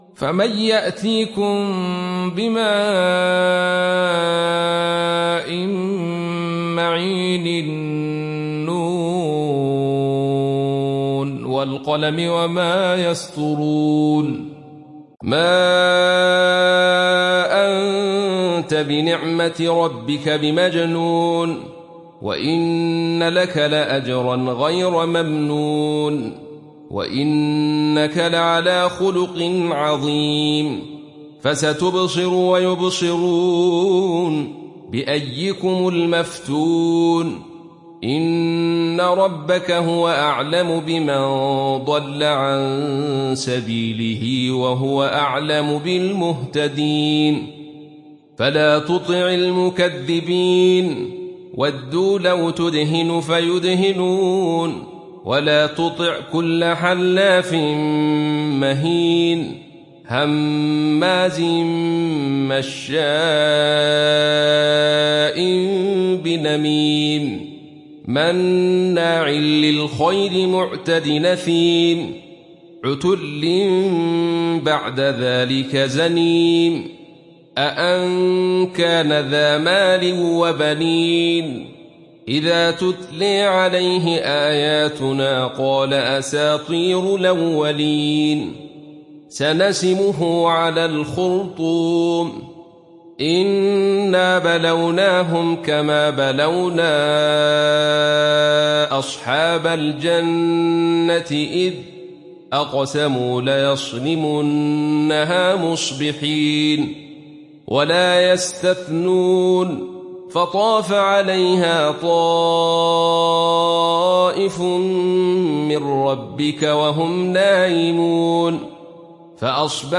تحميل سورة القلم mp3 عبد الرشيد صوفي (رواية خلف)